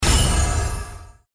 open_card_after.mp3